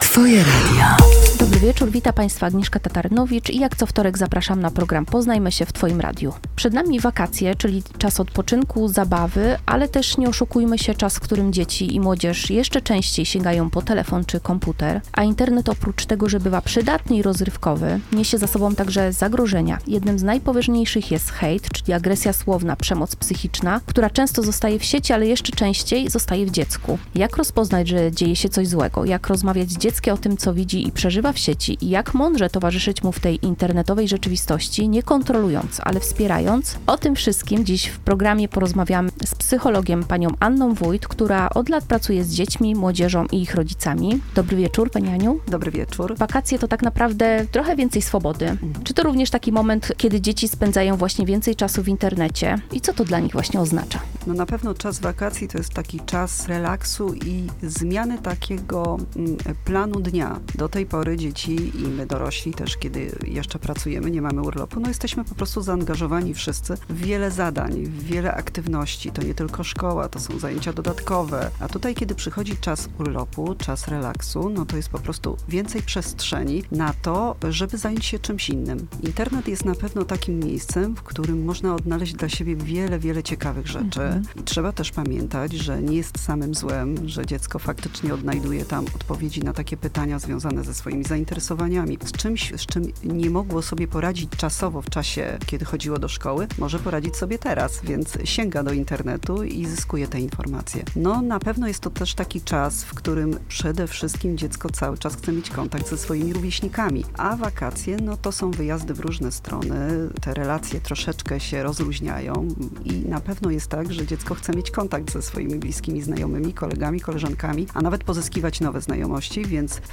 To będzie rozmowa o tym, co niesie za sobą wakacyjna obecność w sieci – czyli więcej swobody, ale też więcej zagrożeń. Hejt, cyberprzemoc, uzależnienie od ekranów, lęk przed byciem offline (FOMO). Jak rozmawiać z dzieckiem o tym, co przeżywa w internecie?